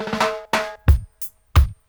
90-FILL-DRY.wav